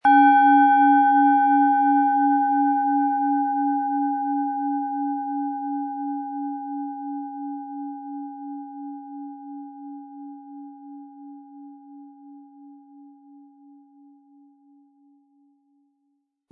Planetenton 1
Handgearbeitete tibetische Schale mit dem Planetenton Merkur.
Durch die überlieferte Fertigung hat diese Schale vielmehr diesen außergewöhnlichen Ton und die intensive Berührung der mit Liebe hergestellten Handarbeit.